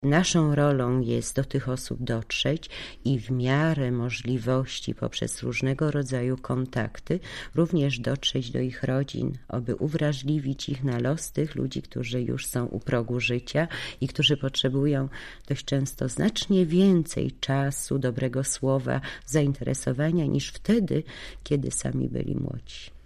W audycji sprzed 25 lat tak mówiła o osobach samotnych i starszych. https